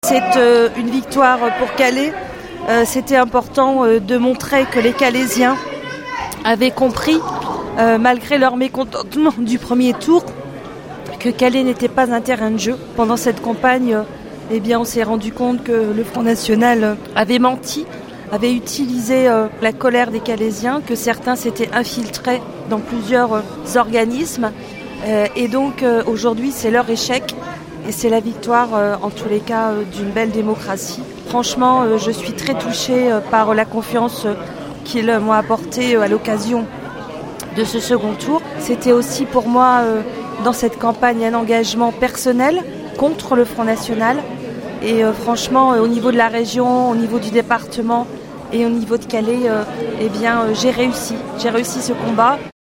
réaction de Natasha Bouchart sénatrice maire de Calais élue conseillère régionale LR